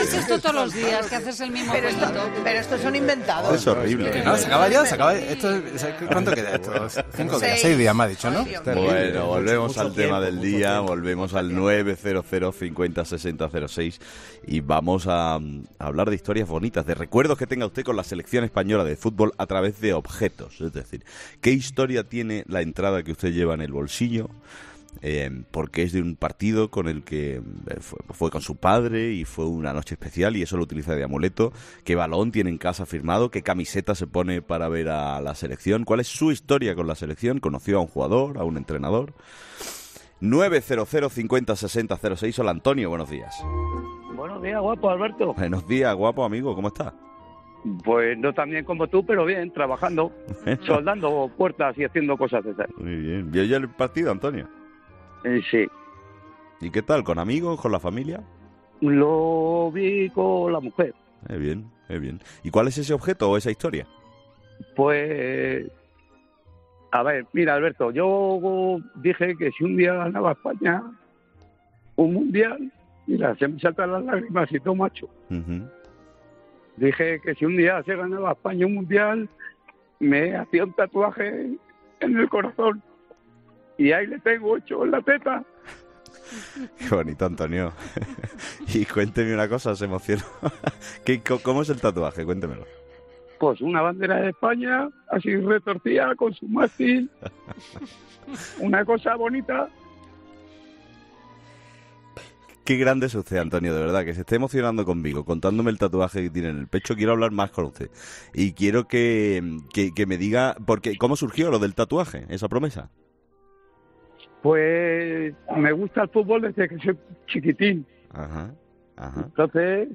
Lo ha dicho entre lágrimas.